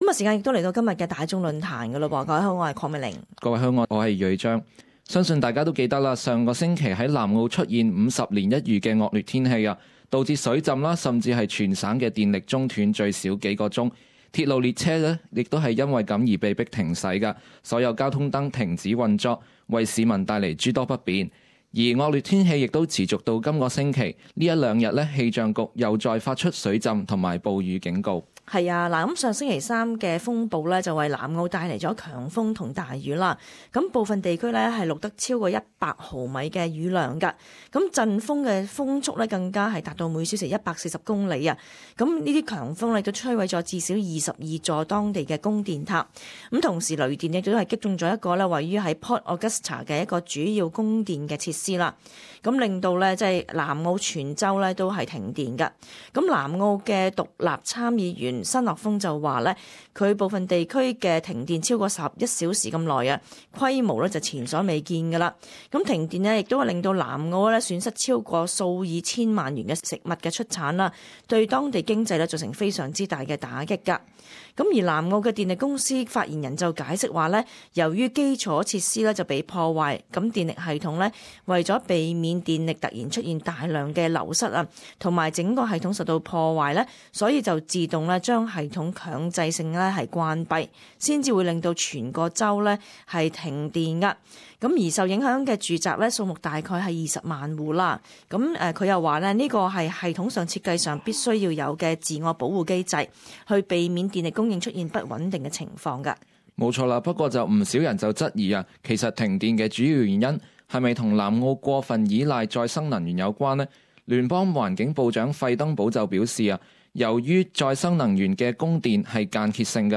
Talkback - How would you live your life without electricity?